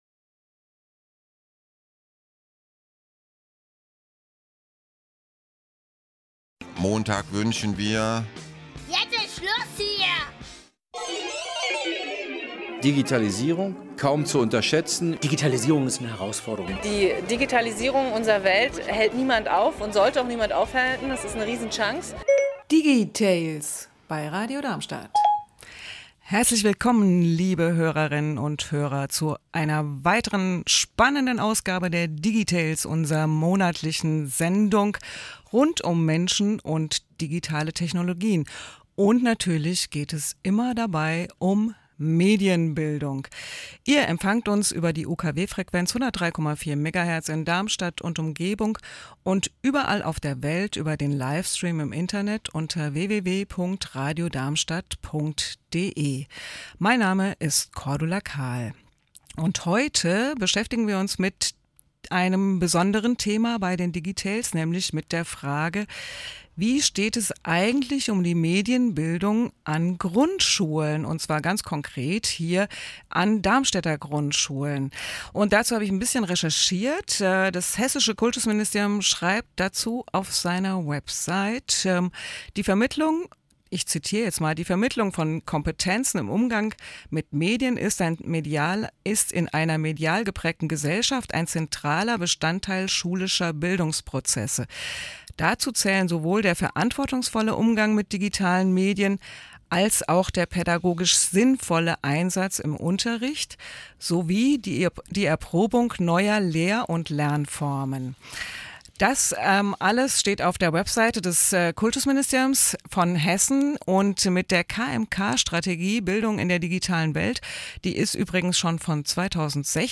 Interview im Radio
Anmerkung: Die Musik aus der Original-Sendung wurde herausgeschnitten.